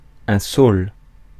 Ääntäminen
France: IPA: /sol/